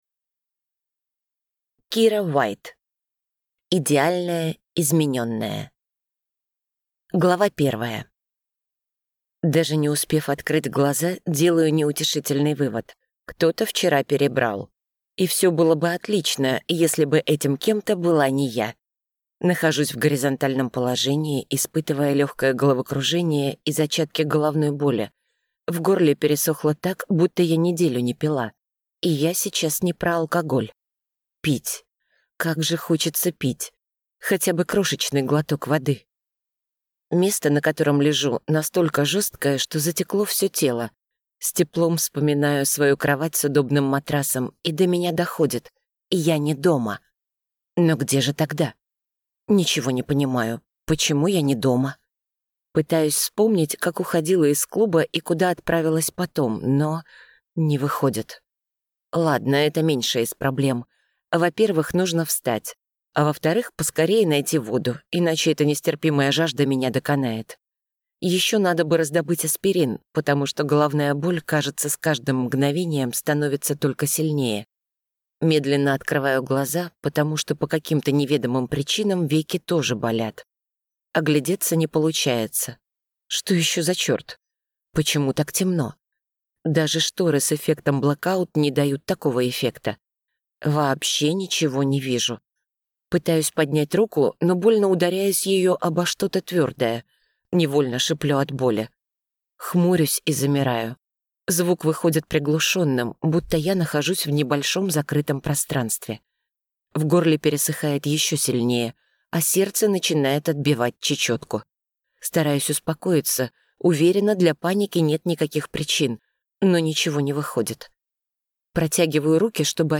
Аудиокнига «Идеальная Изменённая». Автор - Кира Уайт.